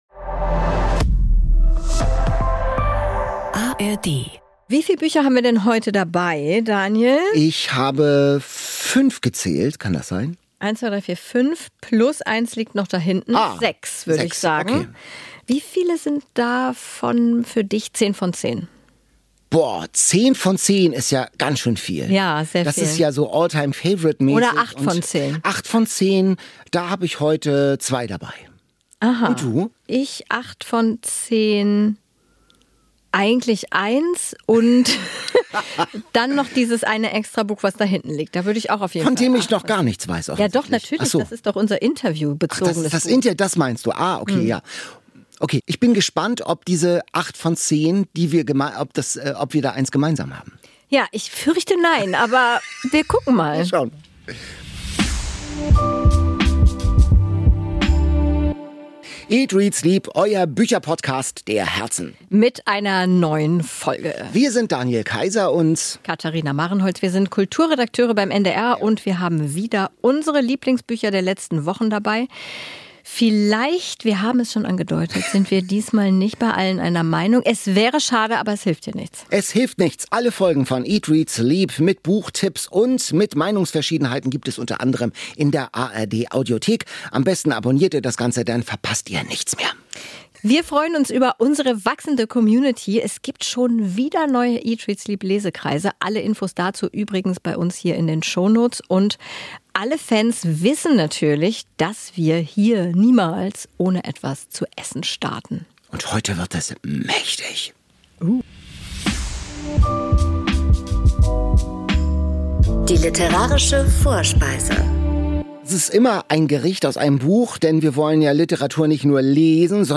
Lieblingsbücher, Neuerscheinungen, Bestseller – wir geben Tipps und Orientierung. Außerdem: Interviews mit Büchermenschen, Fun Facts und eine literarische Vorspeise.